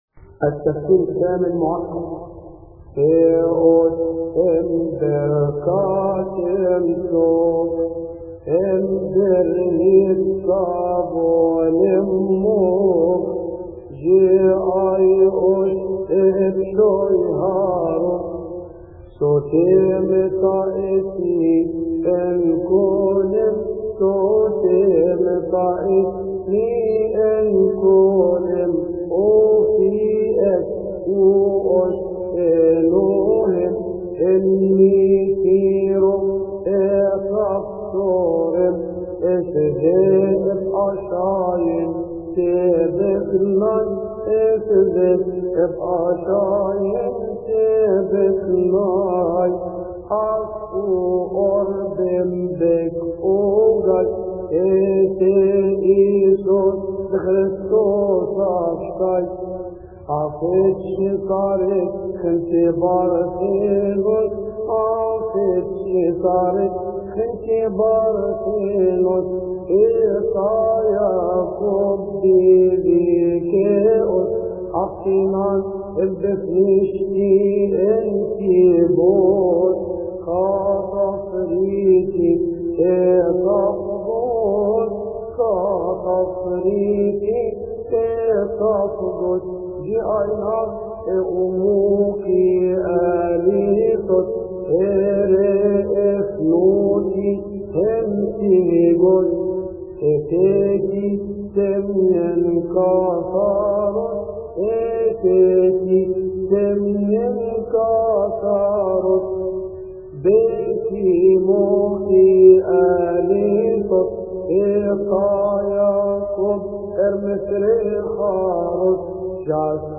يصلي في تسبحة عشية أحاد شهر كيهك